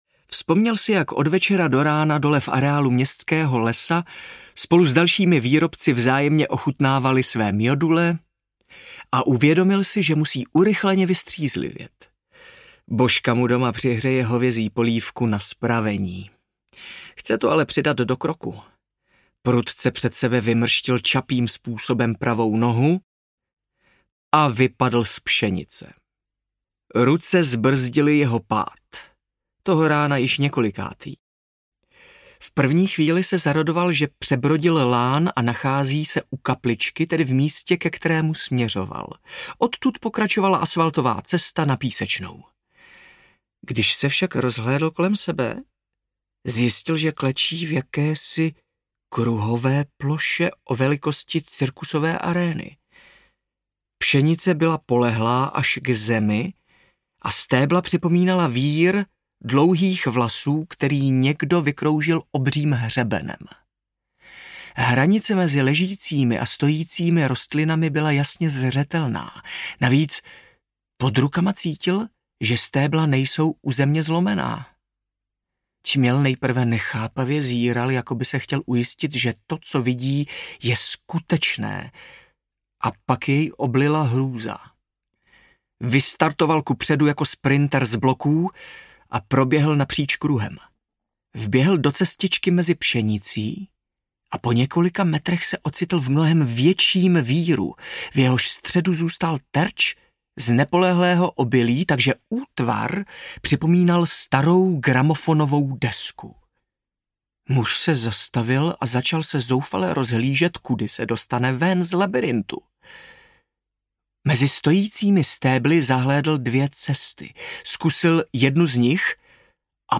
Trujkunt I. – Kruhy audiokniha
Ukázka z knihy
trujkunt-i-kruhy-audiokniha